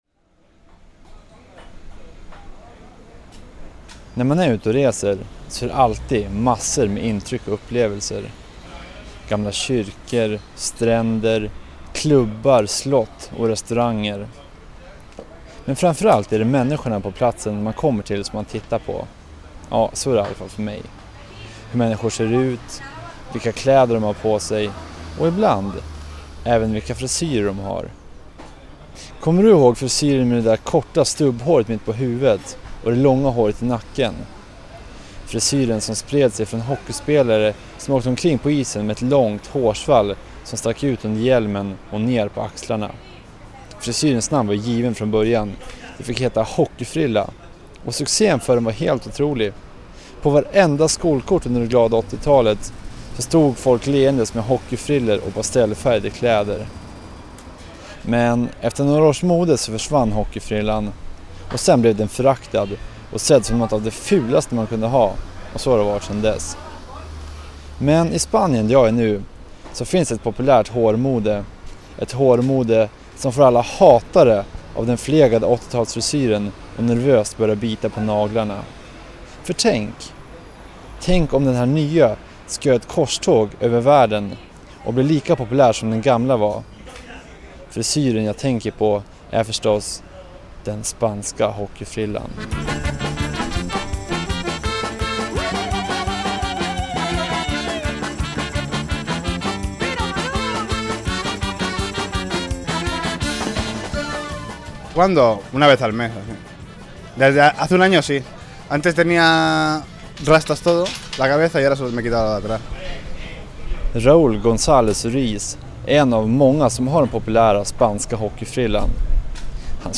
Reportaget sändes i P3-programmet Transit.